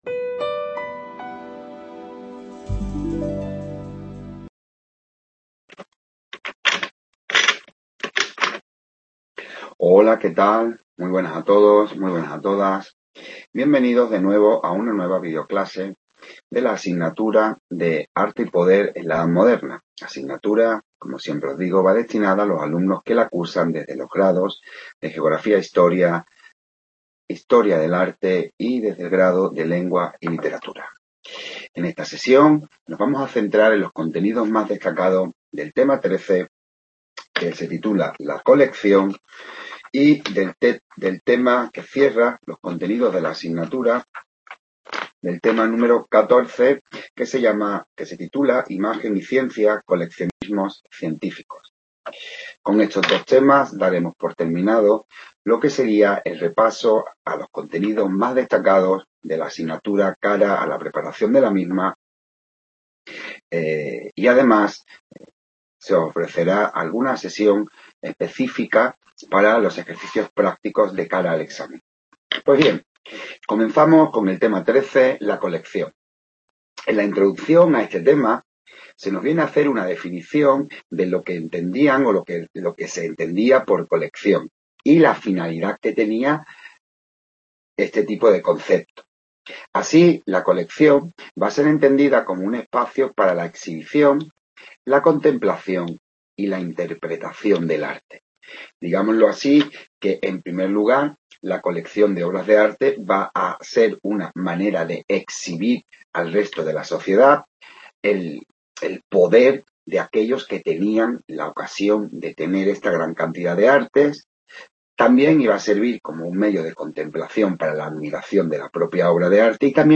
En esta videoclase se pretende ofrecer un repaso por los contenidos más destacados de los temas 13 y 14:- TEMA 13- LA COLECCIÓN - TEMA 14- IMAGEN Y CIENCIA.